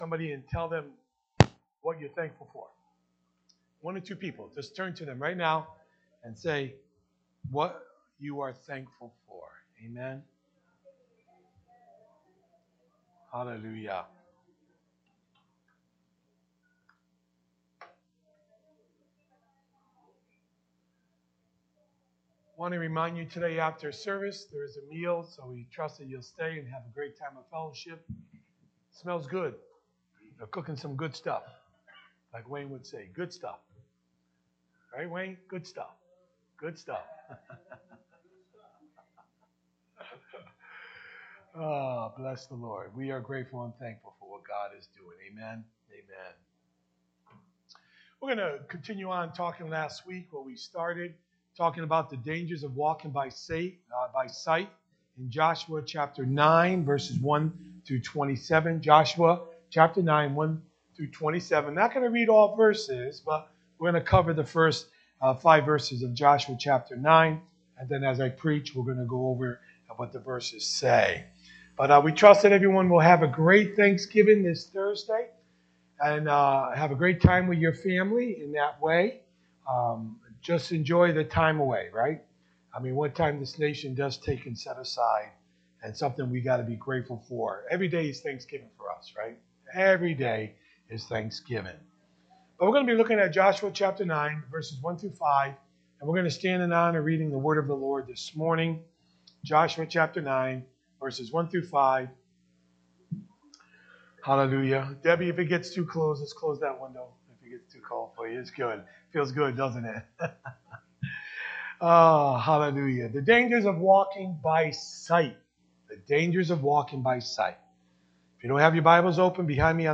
Sermons | Oneonta Assembly of God